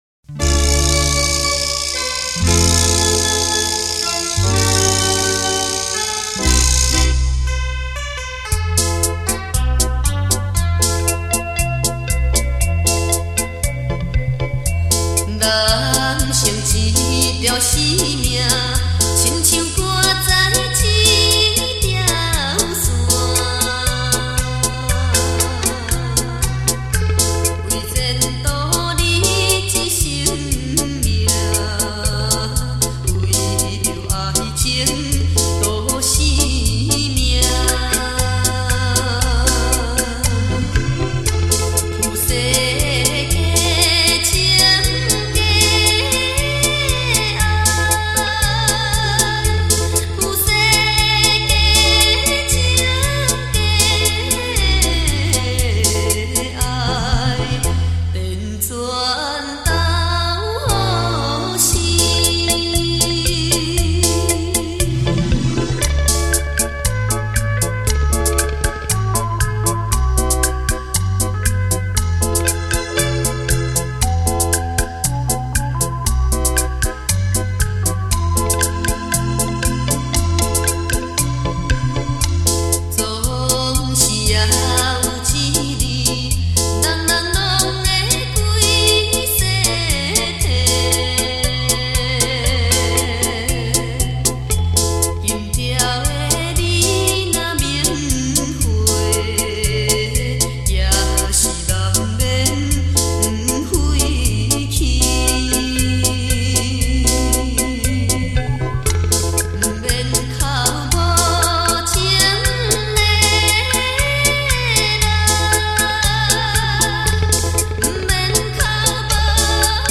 酒廊卡拉OK